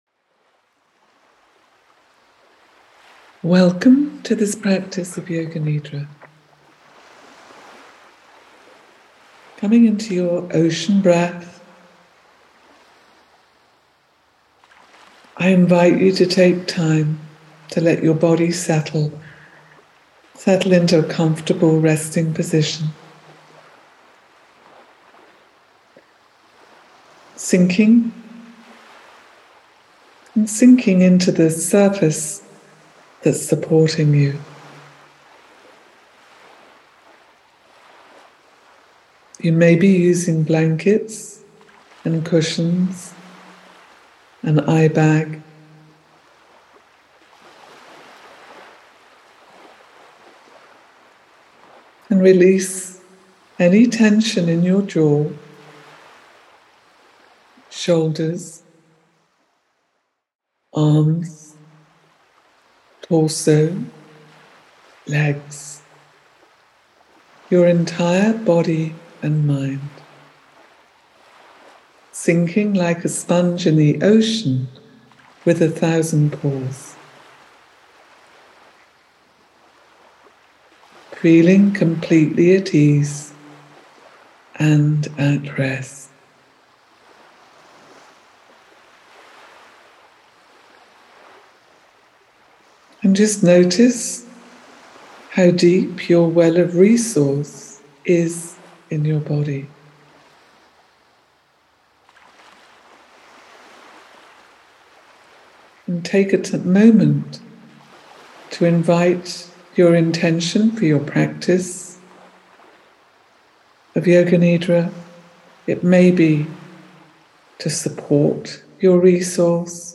I have led retreats in Dorset, Morocco, and Egypt and you can find my up and coming retreats here: Retreats As a gift for visiting my new site I would love to offer you a relaxing free ocean guided yoga nidra meditation.